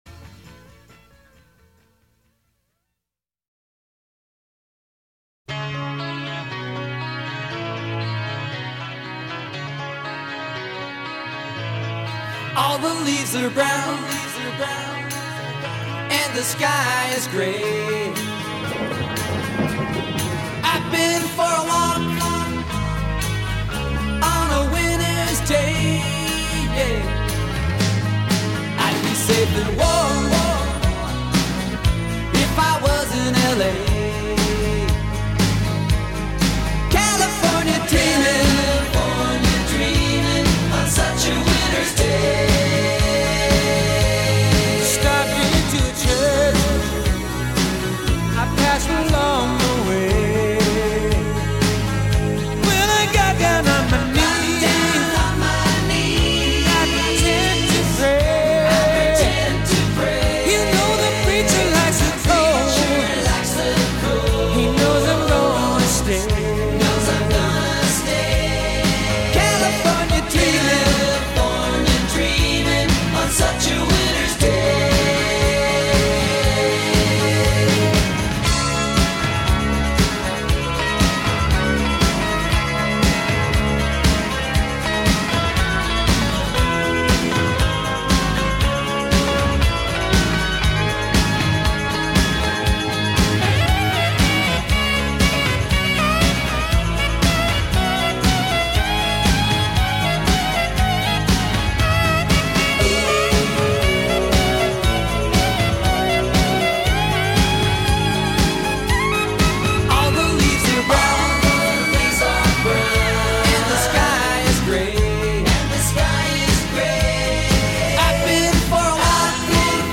Genre: Pop/Rock/Surf